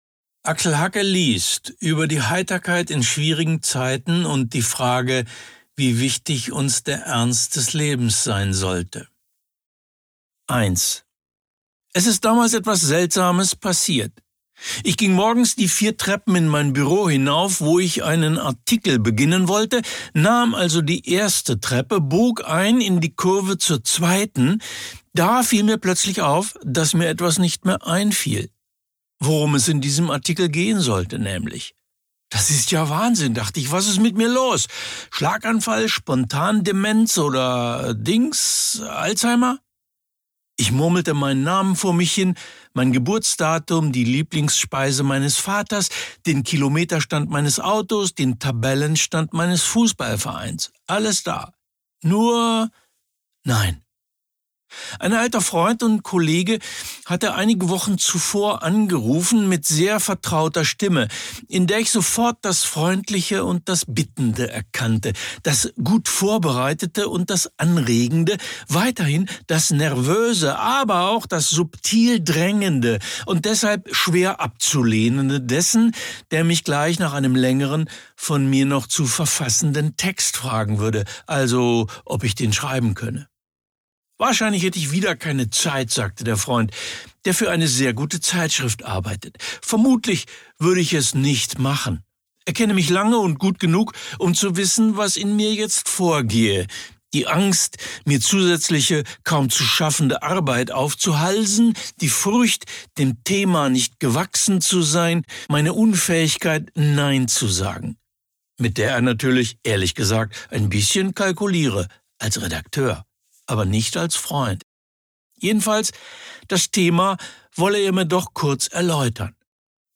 Axel Hacke (Sprecher)